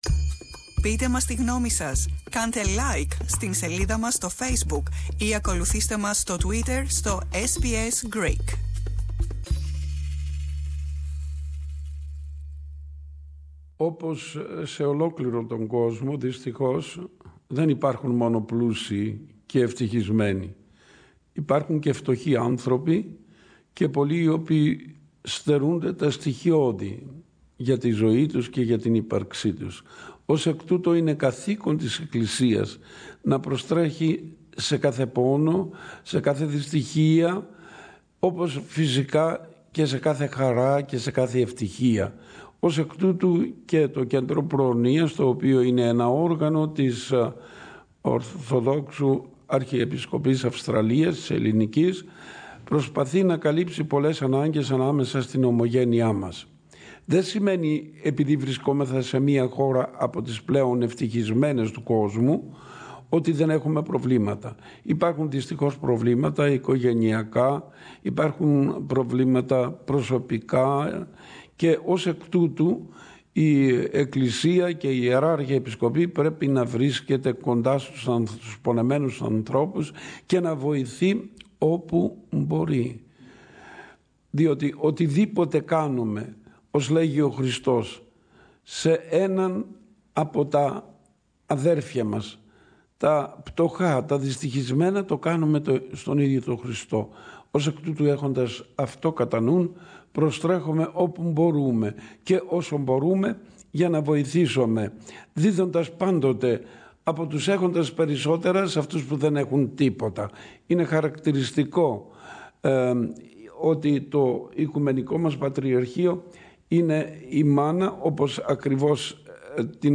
His Eminence Bishop Ezekiel of Dervis spoke tp SBS Greek about the annual Archdiocese's Christmas Appeal and Christmas Carols, and about the 2018 challenges.